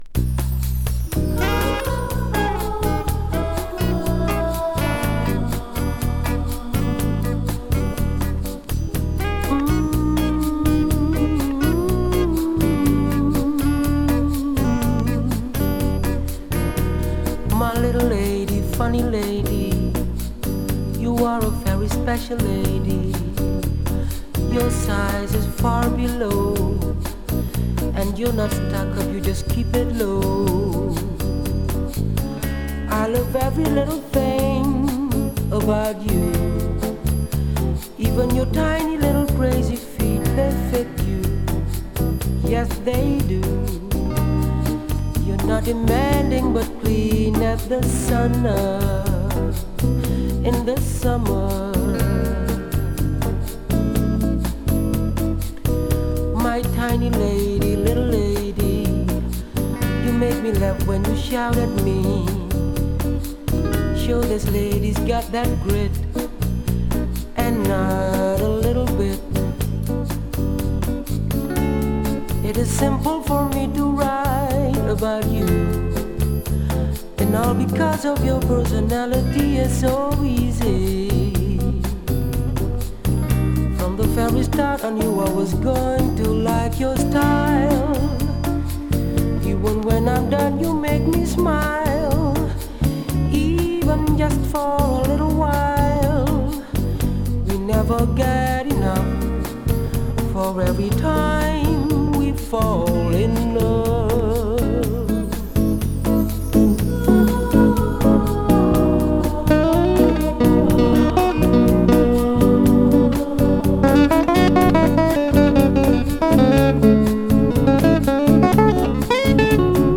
Urban Boogie from Holland!
[BOOGIE]